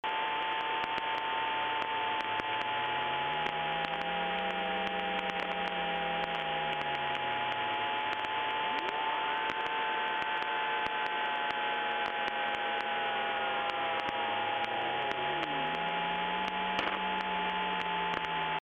> Started getting this interference a while back on 137.780 (or
> source, at other times there's several sweeping sources of different signal
> varies ... typically the on/off ratio runs roughly 1/2 to 1/5 or so.
interference.mp3